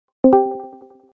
discord_join.wav